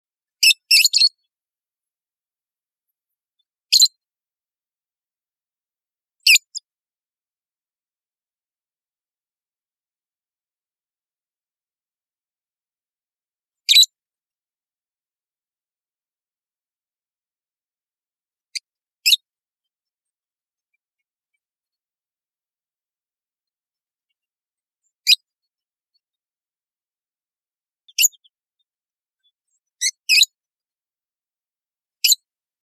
Rosy-faced Lovebird | Ask A Biologist